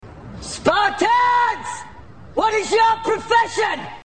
Категория: Скачать Фразы и Произношения